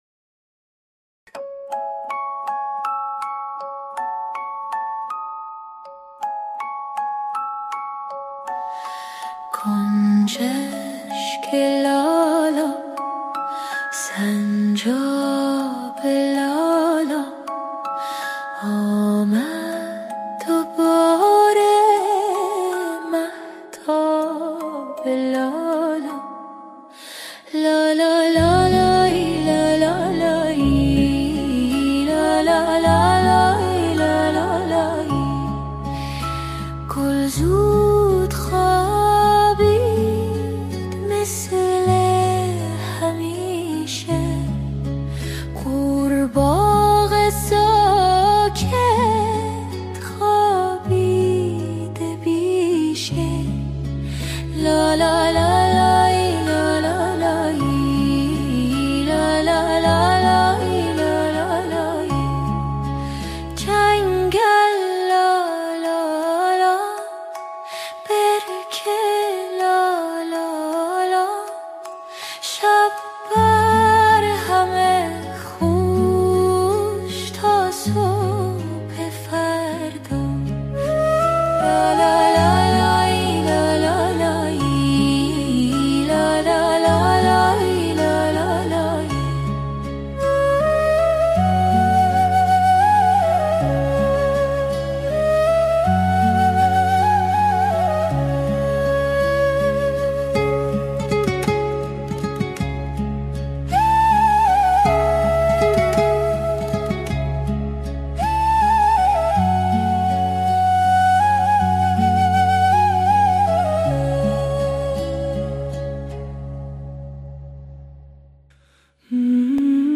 Instruments: Piano, violin, hang drum, Guitar🎧
Vocals: Female🎙